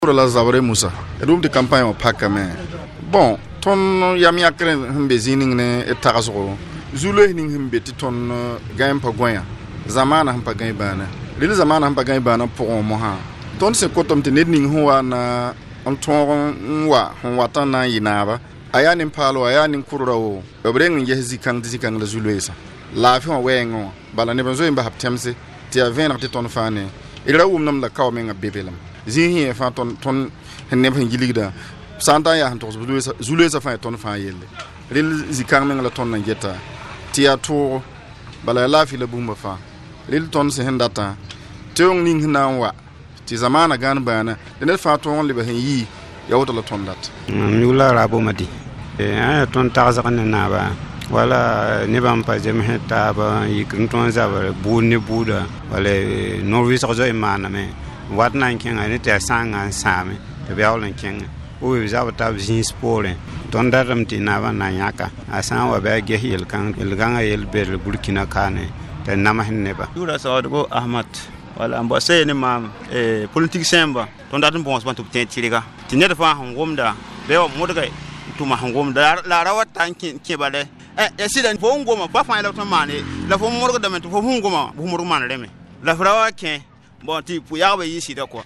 MICRO-TROTTOIR-MORE-ATTENTES-DES-POPULATIONS-APRES-LA-CAMPAGNE.mp3